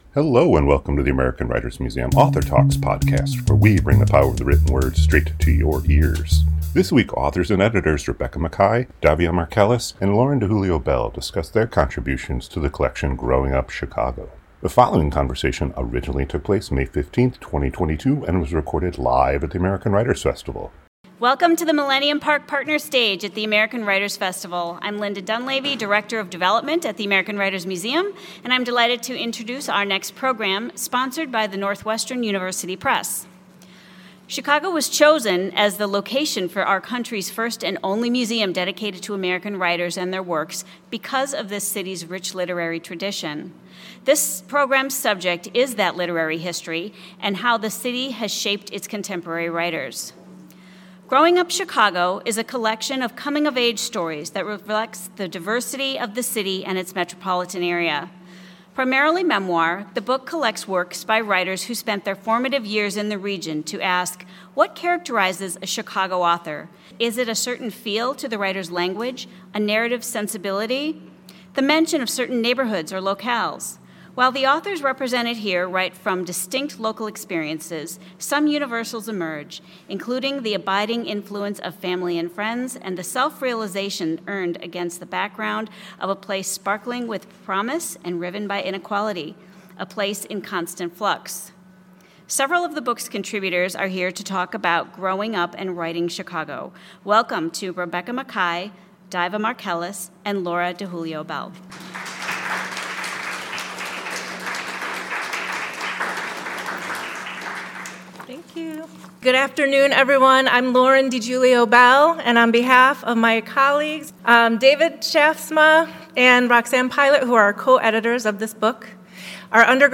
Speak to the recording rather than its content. This conversation originally took place May 15, 2022 and was recorded live at the American Writers Festival.